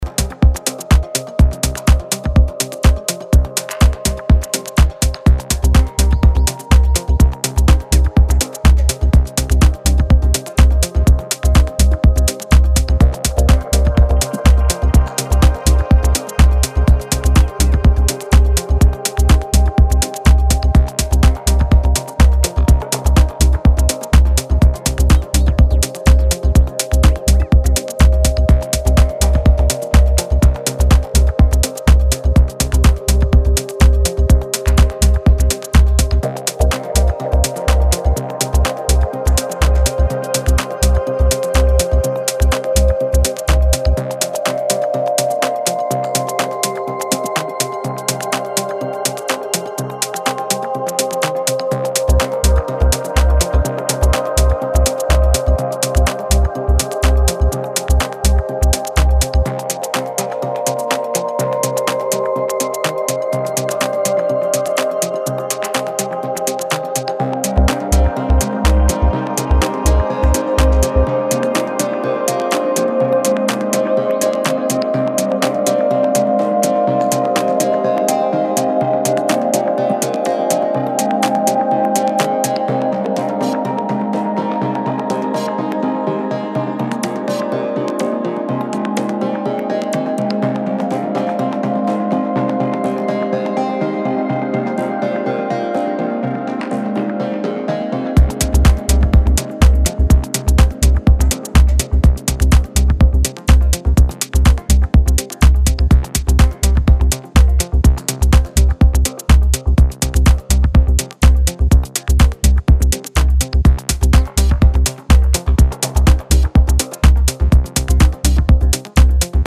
3 original tracks in different moods for the dancefloor